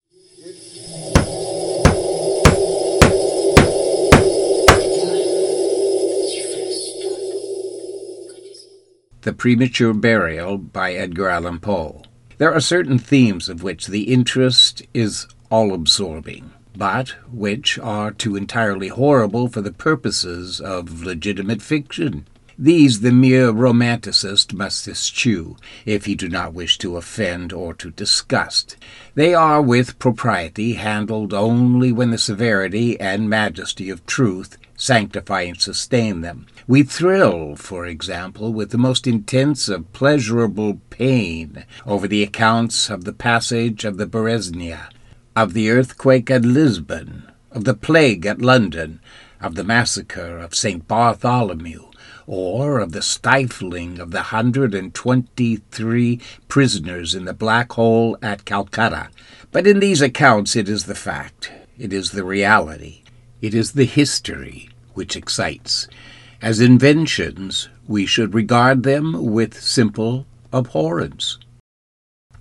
Audio Book – The Premature Burial – Edgar Allan Poe